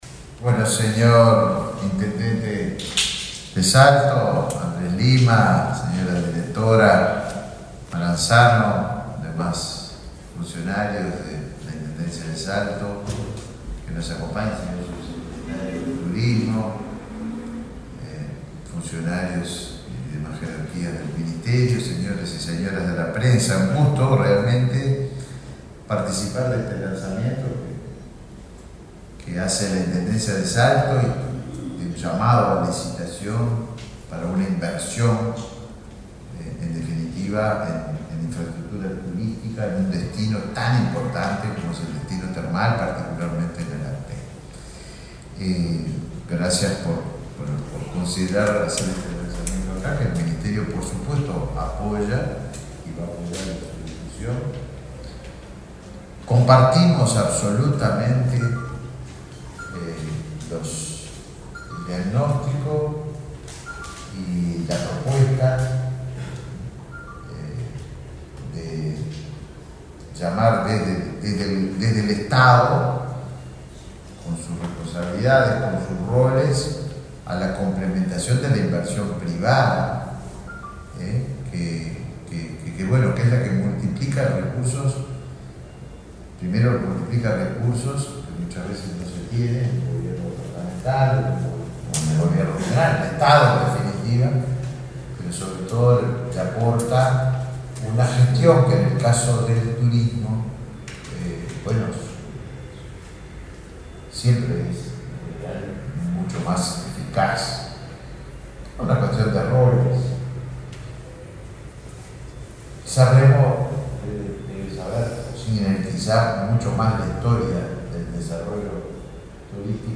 Palabras del ministro de Turismo, Tabaré Viera
Palabras del ministro de Turismo, Tabaré Viera 21/12/2023 Compartir Facebook X Copiar enlace WhatsApp LinkedIn El ministro de Turismo, Tabaré Viera, participó, este jueves 21 en la sede de la cartera que dirige, en la presentación del llamado a licitación para la explotación comercial del Hotel Municipal de Termas del Arapey, en Salto.